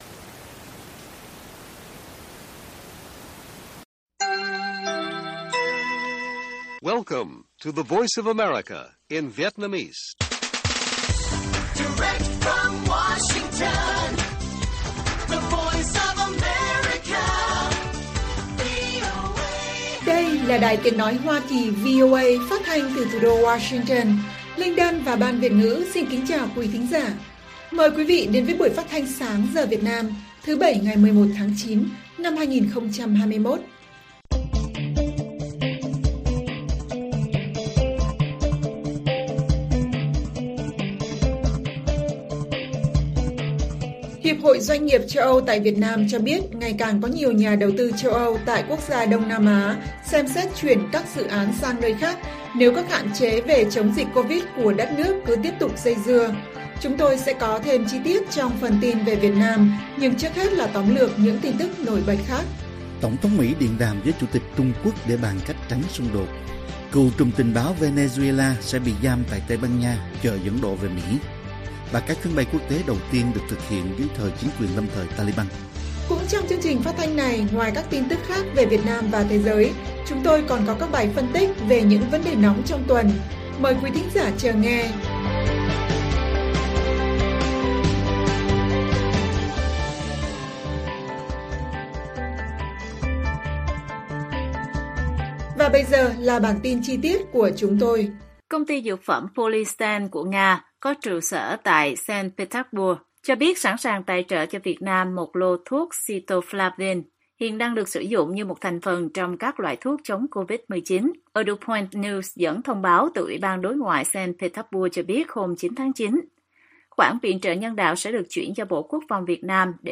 Bản tin VOA ngày 11/9/2021